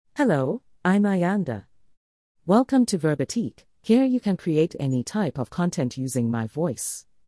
Ayanda — Female South African English AI Voice | TTS, Voice Cloning & Video | Verbatik AI
Ayanda is a female AI voice for South African English.
Voice sample
Listen to Ayanda's female South African English voice.
Ayanda delivers clear pronunciation with authentic South African English intonation, making your content sound professionally produced.